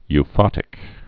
(y-fŏtĭk)